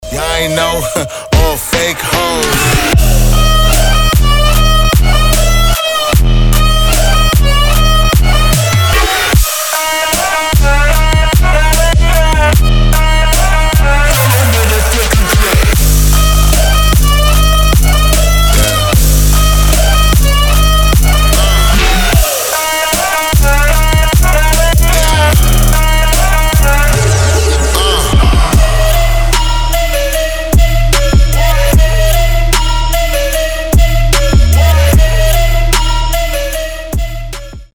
• Качество: 320, Stereo
громкие
мощные
EDM
Trap
Bass